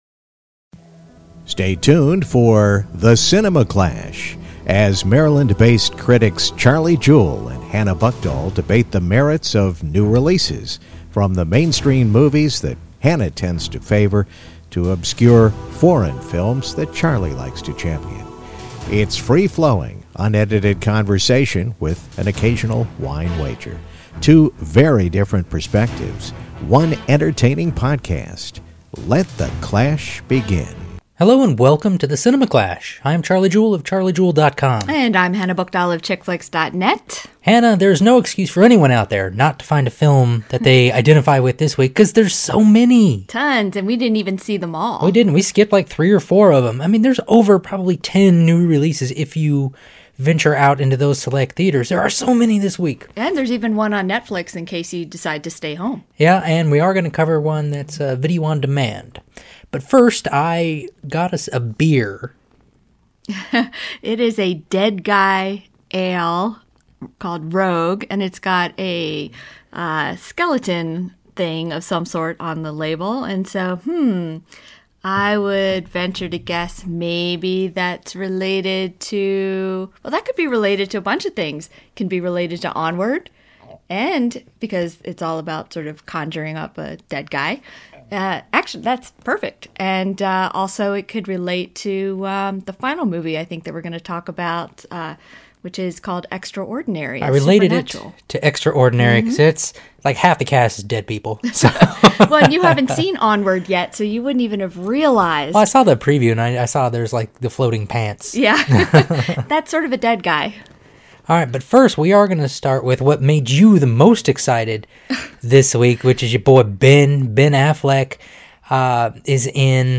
It’s free-flowing, unedited conversation, with the occasional wine wager.